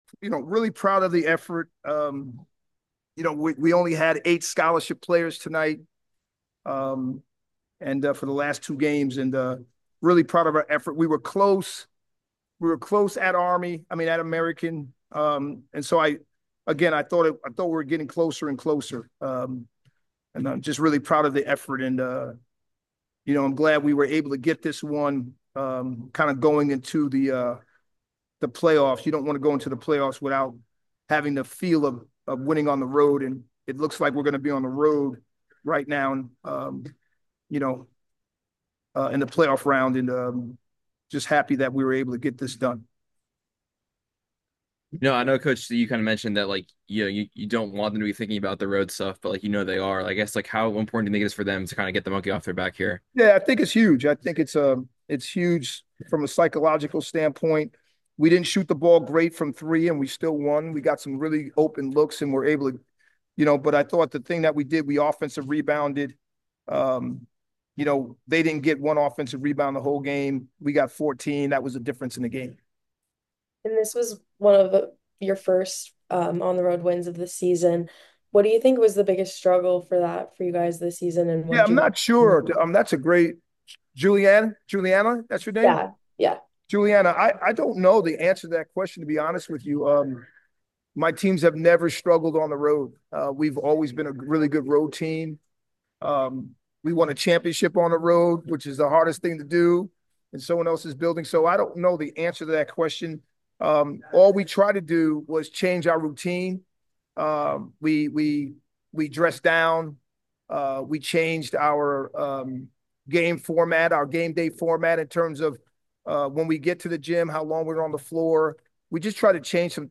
Lehigh Postgame Interview (2-26-25)